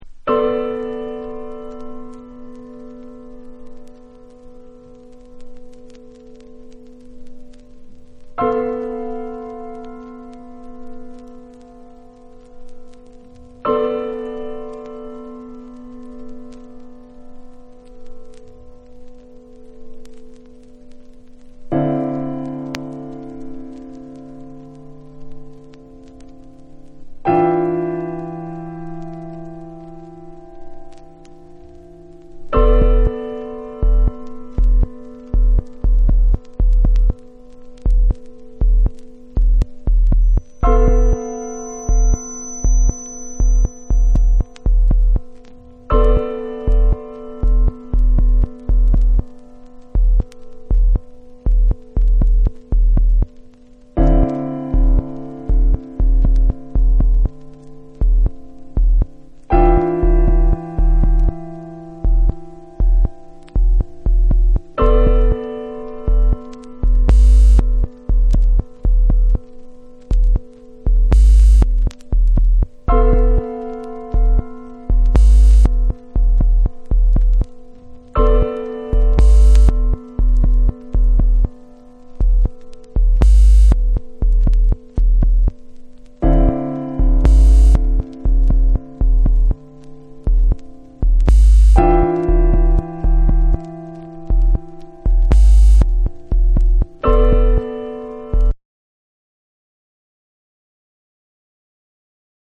NEW AGE & OTHERS / CHILL OUT